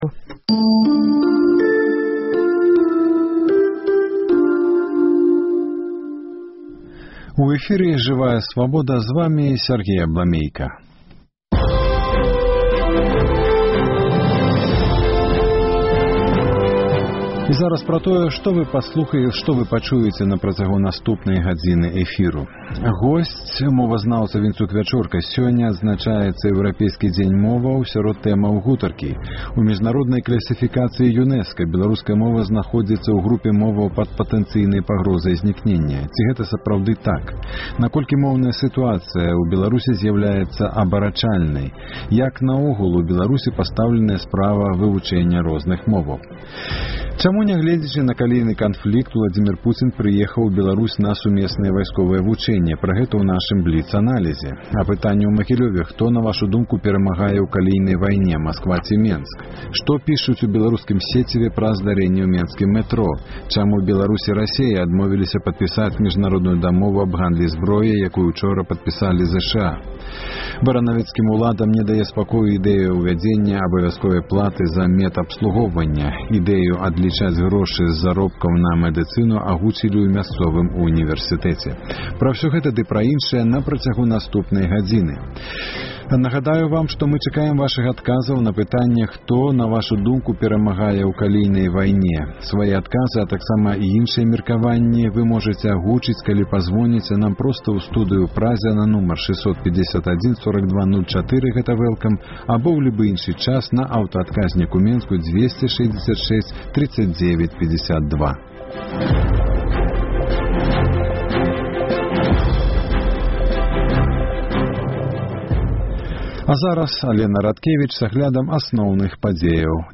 Апытаньне ў Магілёве.